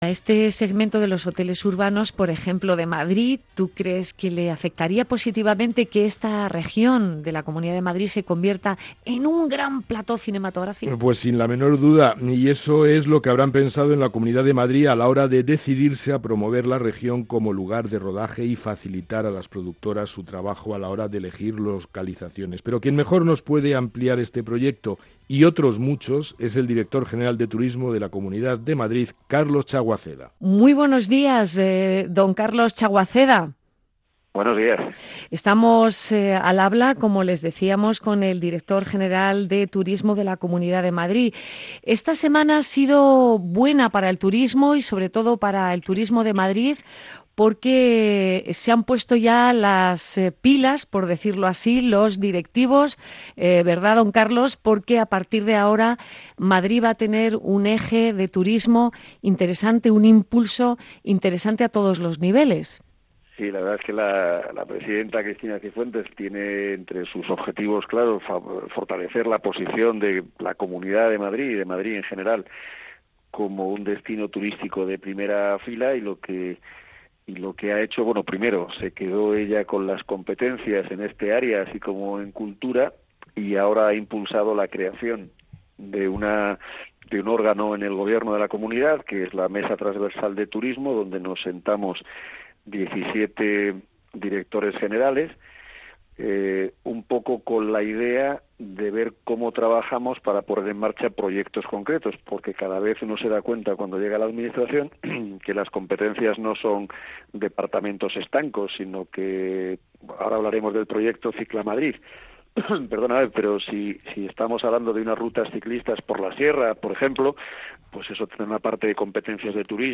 Quiere hacer de Madrid una marca y para ello ha lanzado dos nuevas campañas. Para explicarlas estuvo en “Vivir viajar” el Director General de Turismo, Carlos Chaguaceda.
Entrevista al director de Turismo de Madrid